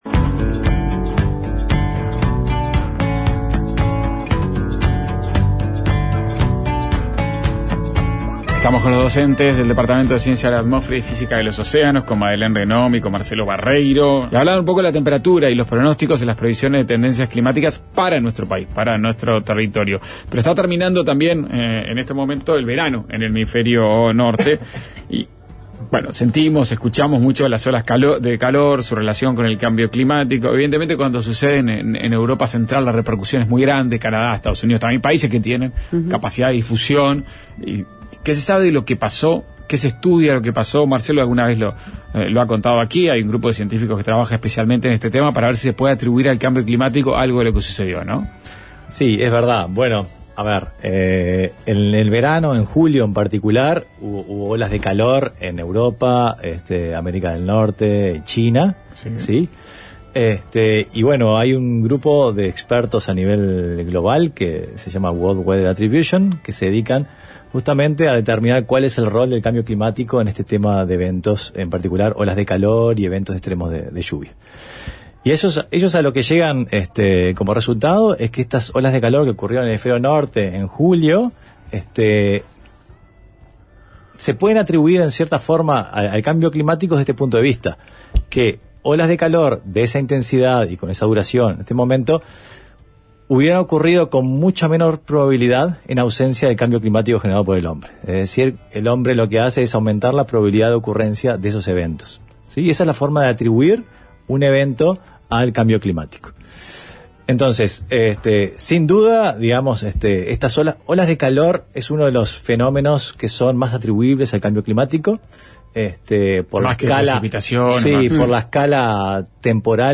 Ronda con los profesores del Departamento de Ciencias de la Atmósfera